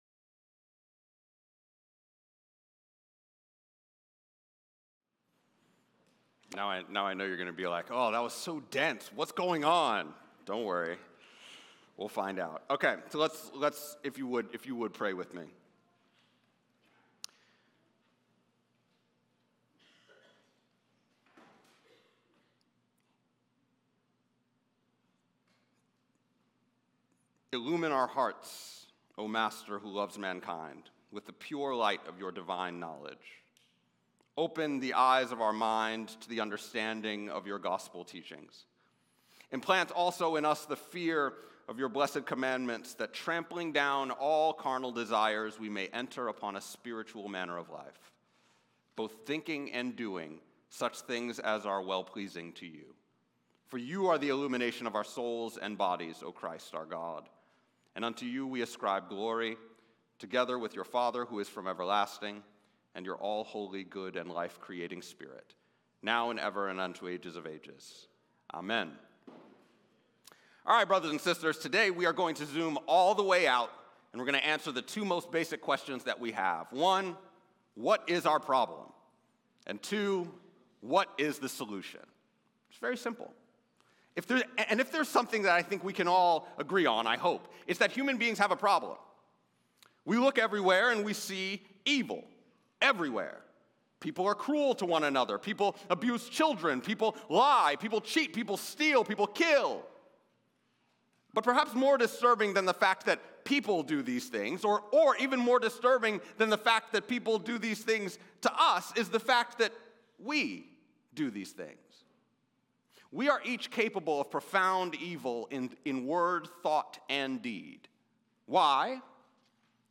9AM Service Feb 22nd 2026